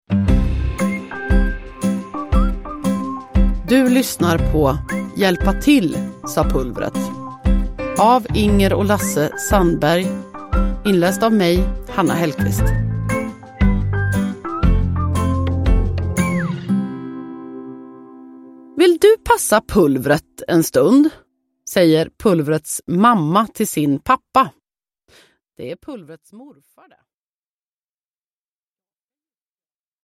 Hjälpa till, sa Pulvret – Ljudbok – Laddas ner
Uppläsare: Hanna Hellquist